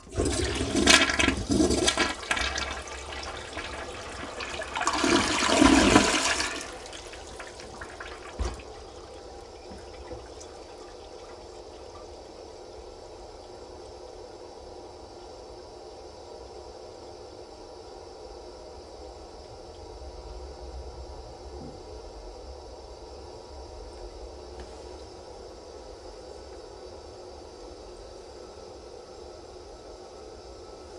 描述：花洒流水滴水马桶
标签： 跑步 淋浴器 厕所
声道立体声